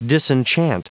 Prononciation du mot disenchant en anglais (fichier audio)
Prononciation du mot : disenchant